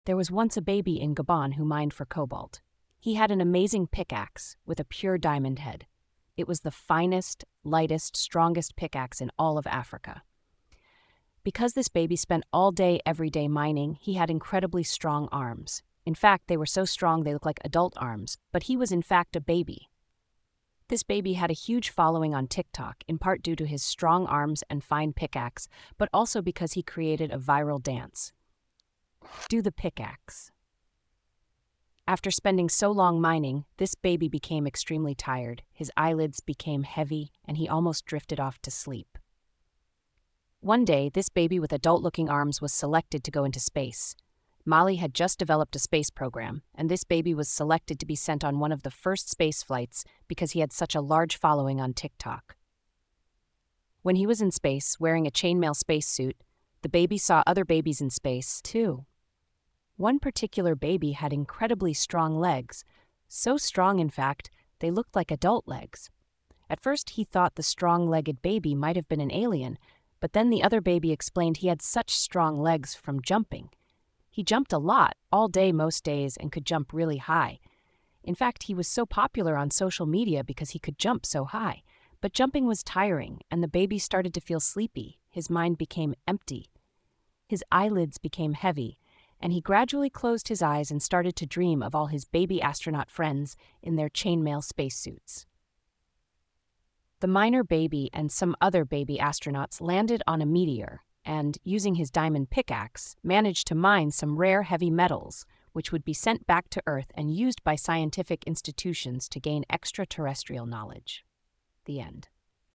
Bedtime Stories